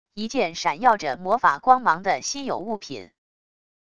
一件闪耀着魔法光芒的稀有物品wav音频